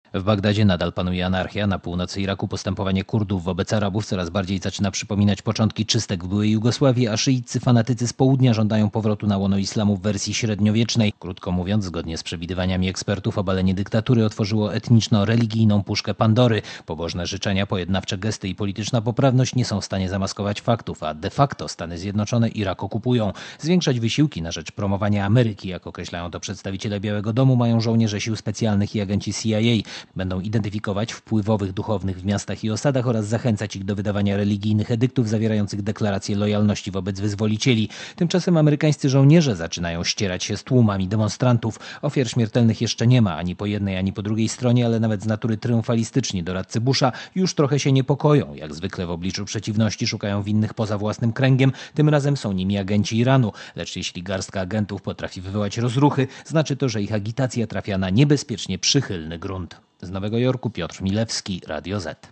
Korespondencja z Nowego Jorku (490Kb)